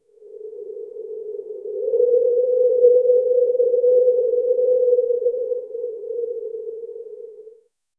The eerie sound of wind howling through a canyon.
the-eerie-sound-of-wind-howling-through-a-canyon-gpf5jpcw.wav